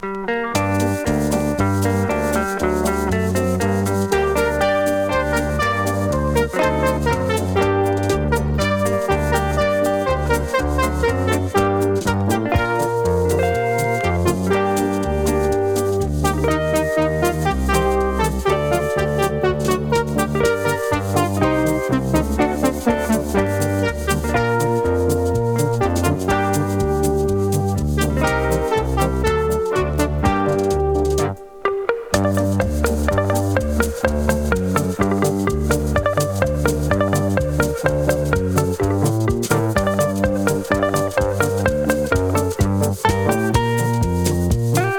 Jazz, Pop, Easy Listening　Canada　12inchレコード　33rpm　Stereo